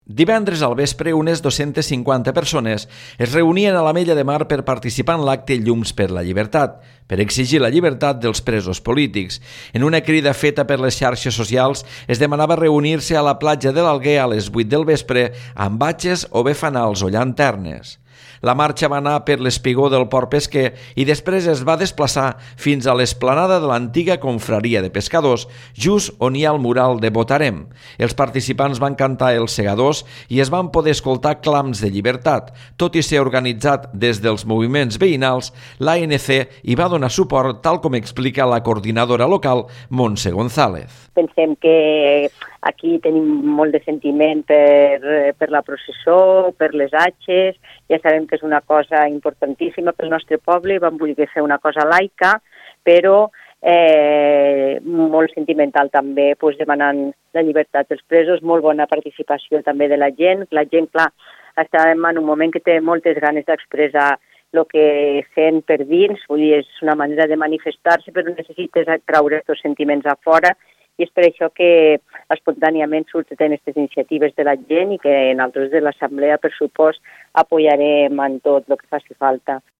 Els participants van cantar ‘Els segadors’ i es van poder escoltar clams de ‘llibertat’.